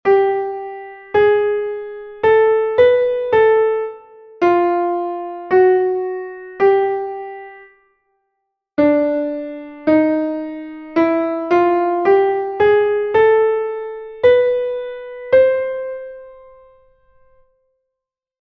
Task 1 ascending chromatic semitone